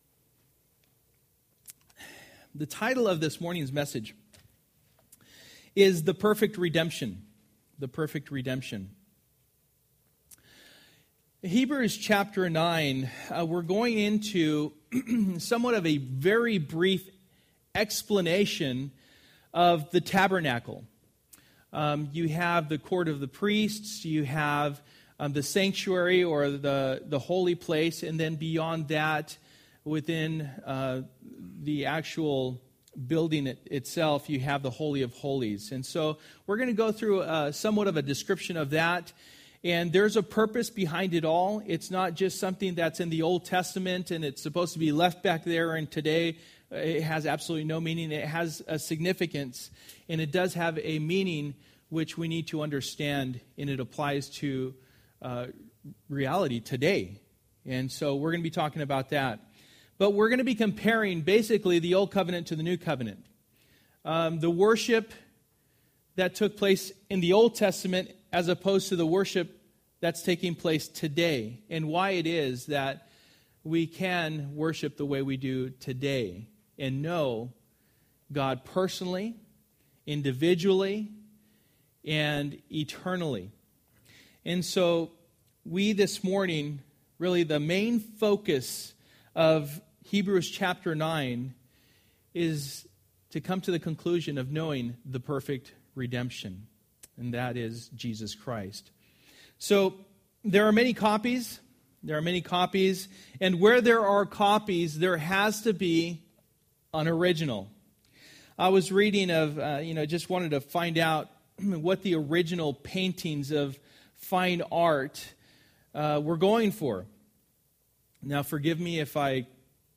Jesus Unparralleled Passage: Hebrews 9:1-1:28 Service: Sunday Morning %todo_render% « From Frying Pan to the Fire!